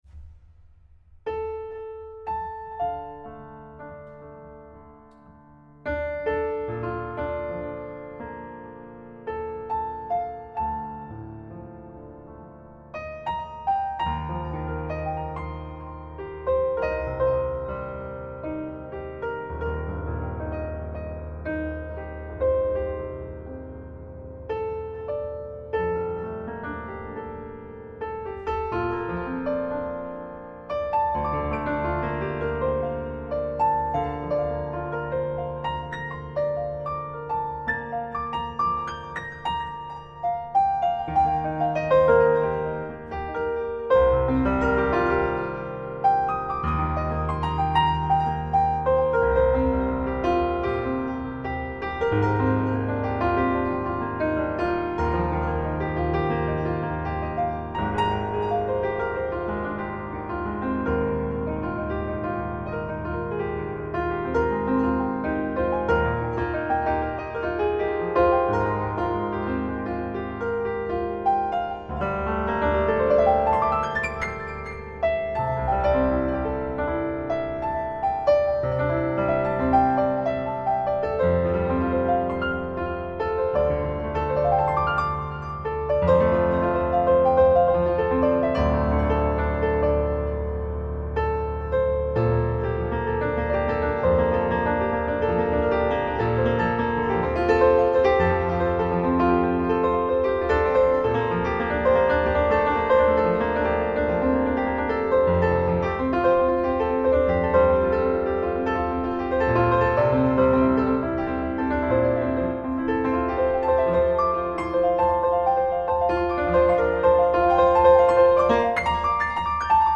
Gocce di Note fantasia al piano